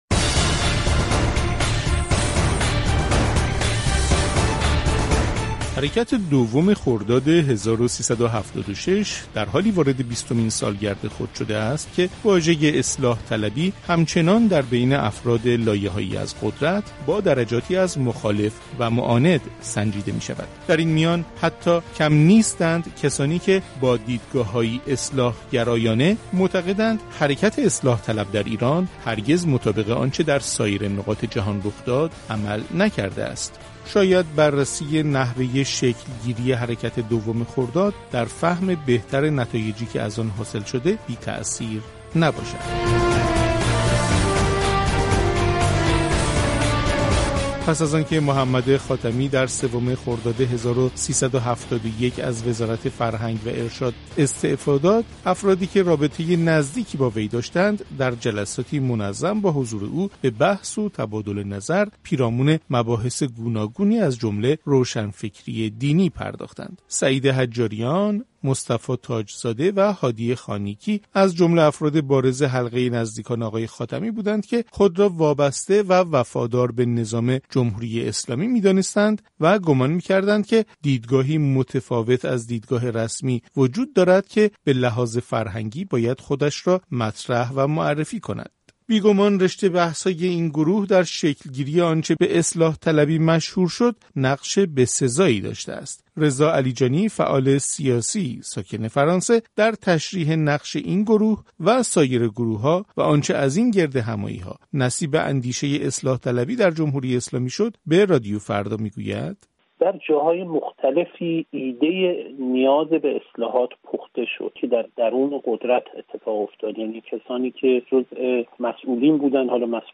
گزارشی